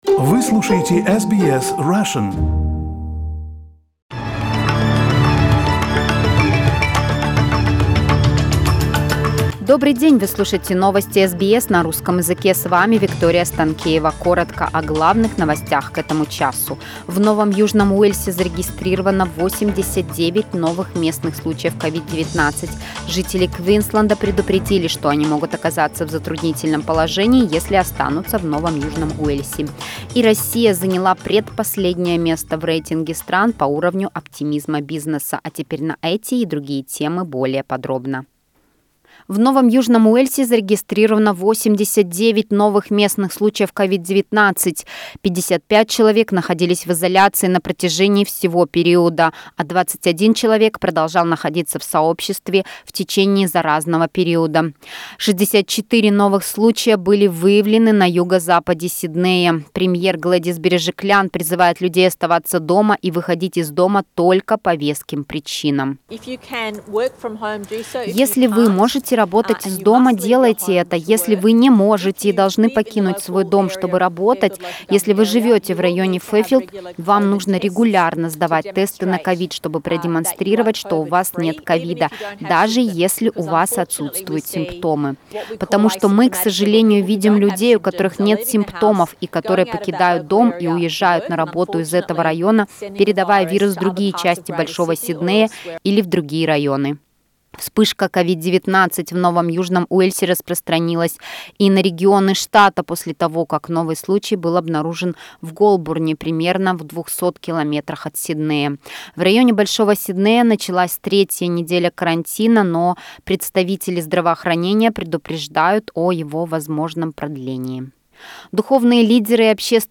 Новости SBS на русском языке - 13.07